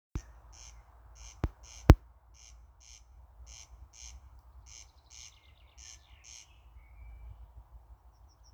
коростель, Crex crex
СтатусПоёт
ПримечанияDzirdēta pļavā starp ezeru un priežu mežu.